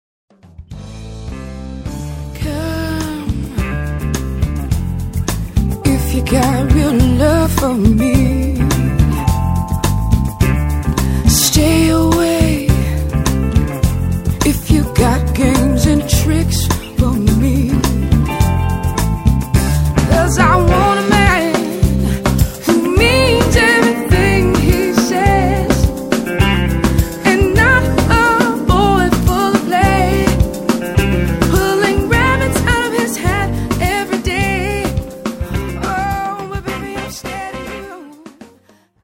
往年のSOUL/FUNKクラシックを完璧に歌いこなす珠玉のカバー集を緊急リリース!